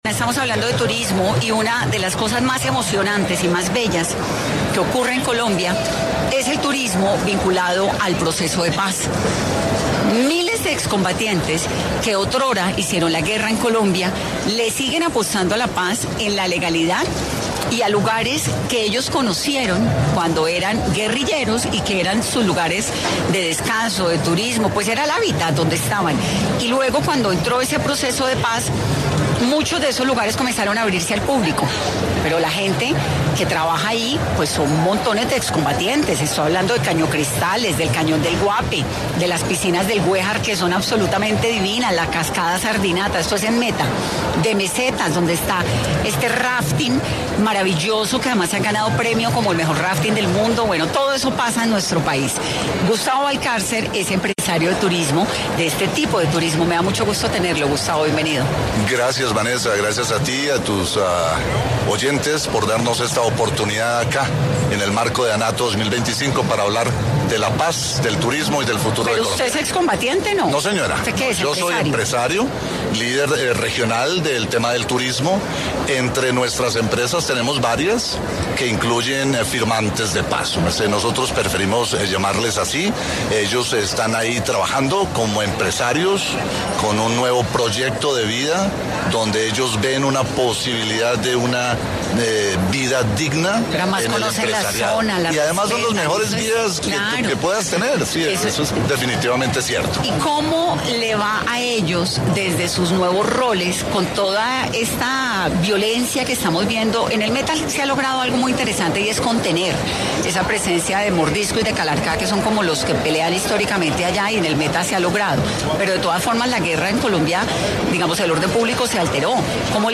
Caracol Radio estuvo presente en Corferias, en donde se llevó a cabo la edición 44 de la vitrina de Anato, el equipo de 10 AM ‘Hoy por hoy’ aprovechó el evento para hablar con varios de los ponentes allí presentes, quienes se refirieron al presente del turismo en las diferentes regiones de Colombia, además de enfatizar en estrategias para recuperar zonas que se vieron envueltas por la violencia y como estas mismas han dejado de ser atractivas con el tiempo para el turismo.